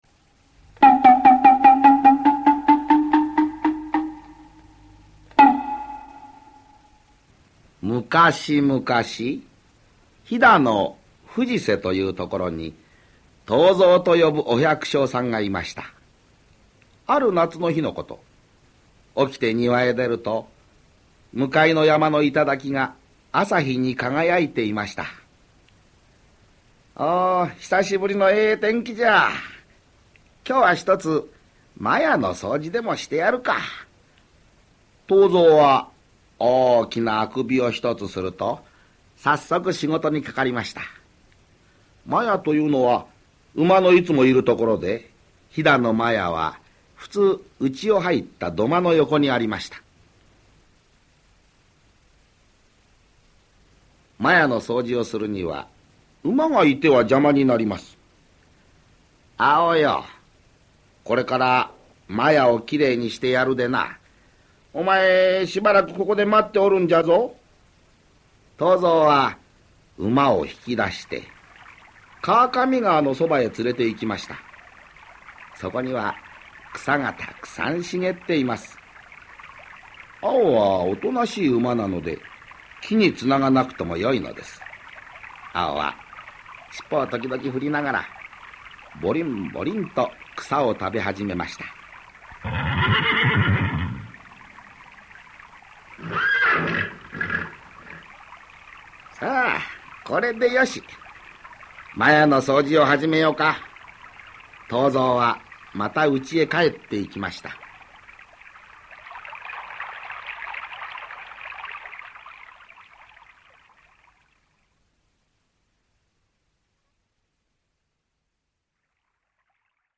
[オーディオブック] 藤蔵とかっぱ